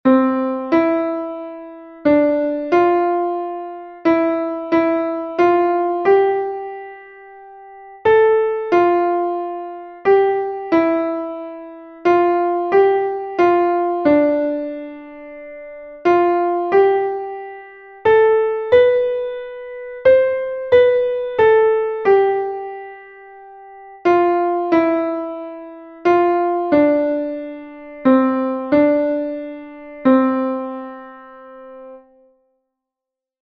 3_melodia_6x8.mp3